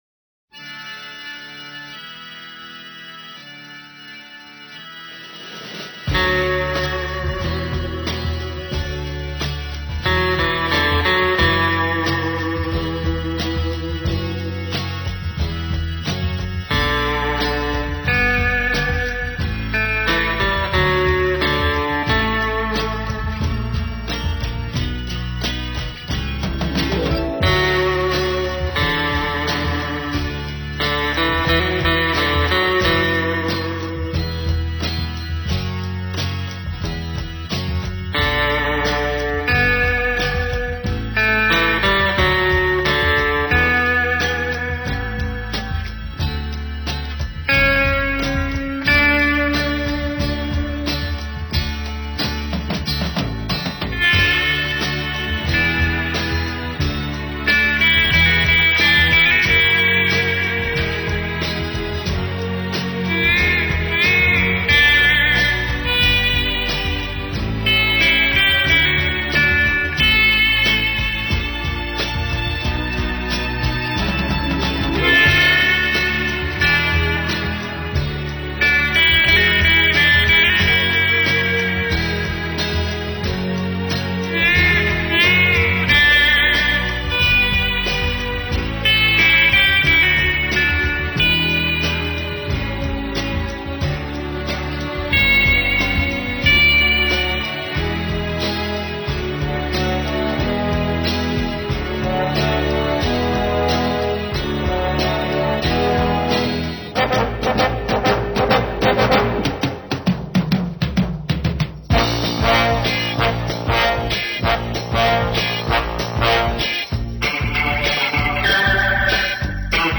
With Orchestra